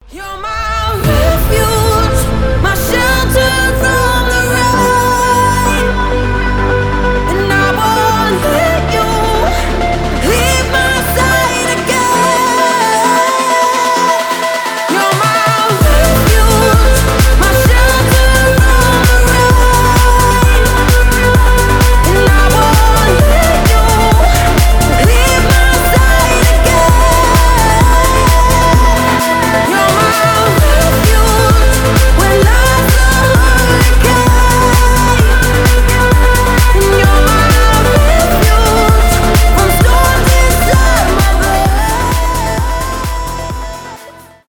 сильный голос
edm
progressive trance